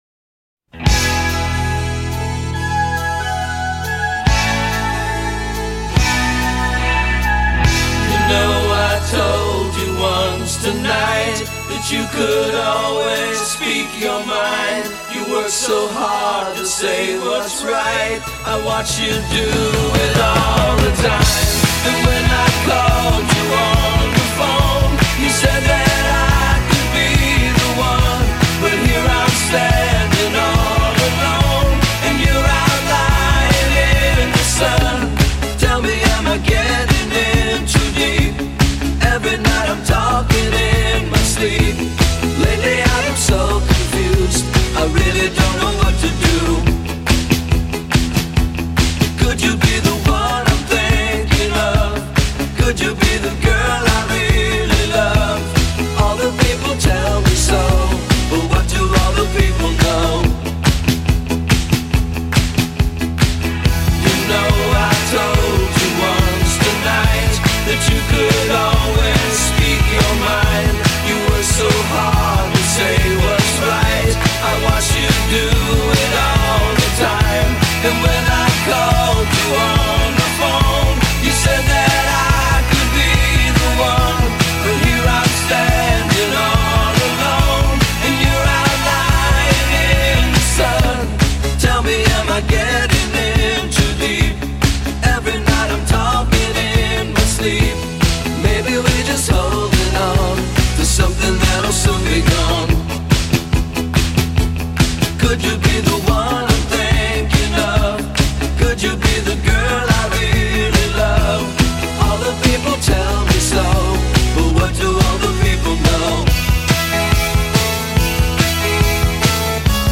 San Diego pop band